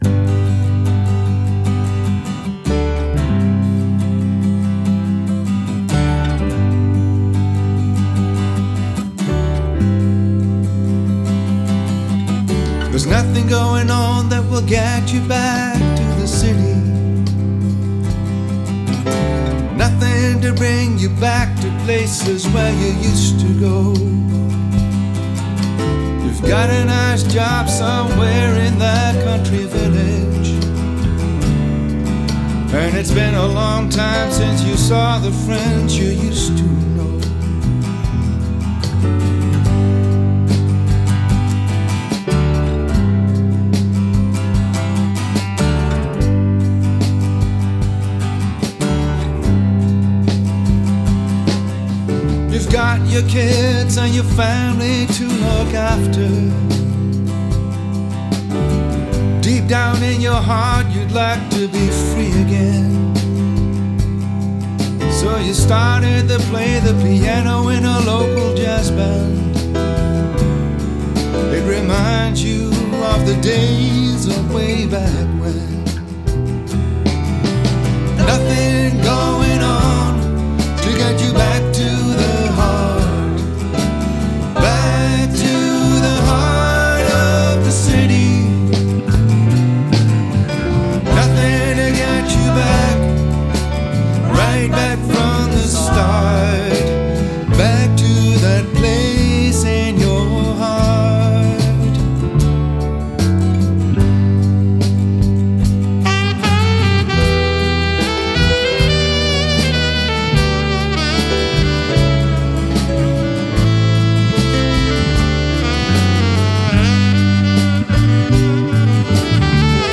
Studio-opnamen 2025